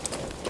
Gear Rustle Redone
tac_gear_40.ogg